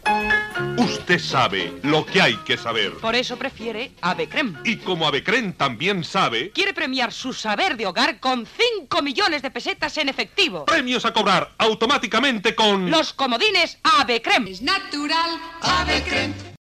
Pécker, José Luis